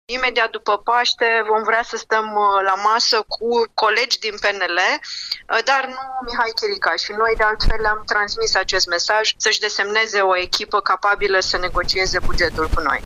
Preşedintele filialei judeţene Iaşi a USR, Cosette Chichirău a precizat că acceptă negocierile cu o echipă din care să nu facă parte Mihai Chirica.